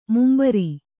♪ mumbari